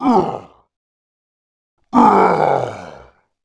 sound2 / sound / monster2 / mutant / dead_1.wav
dead_1.wav